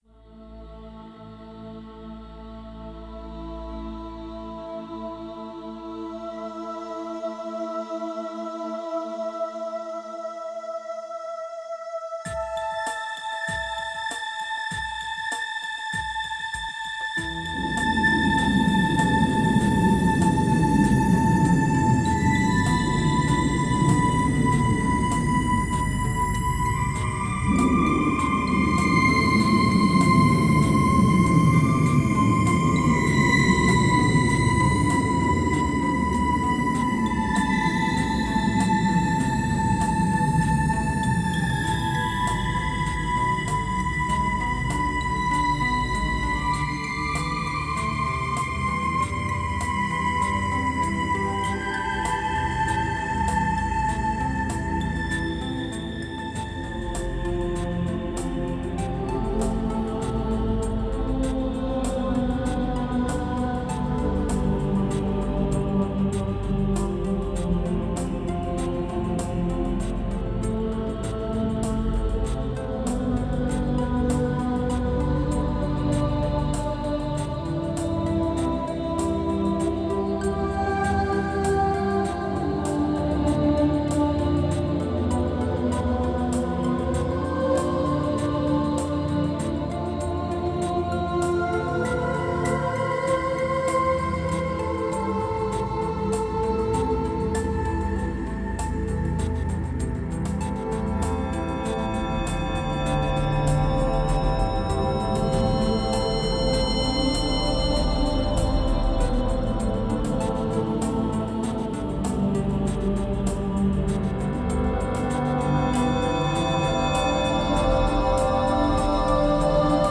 Мистическая музыка Духовная музыка Медитативная музыка